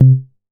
MoogLuBass 002.WAV